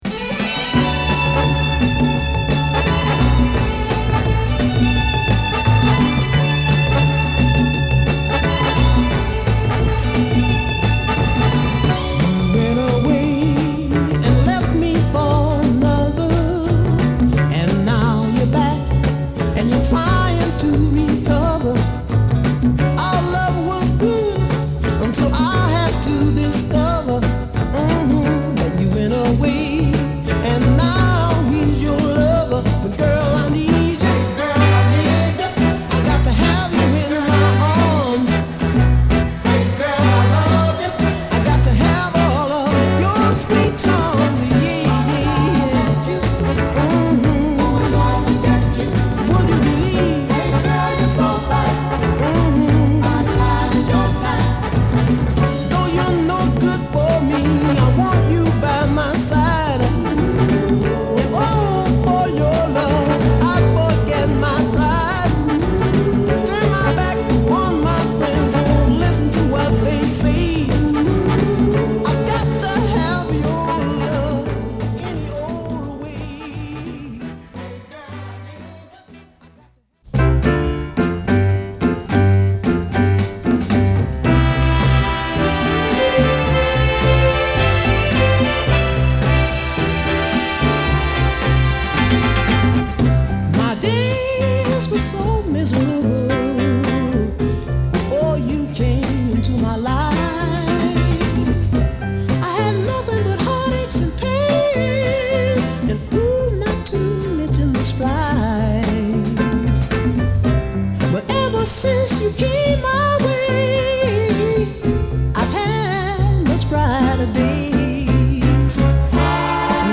タイトルからも想像できる若さ溢れるアップリフティングなナイス・ノーザンナンバー